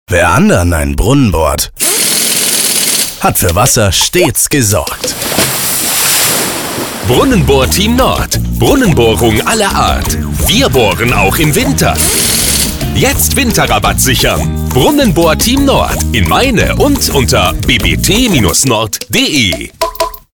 BBT-Brunnenbohrteam-Nord-Radiospot.mp3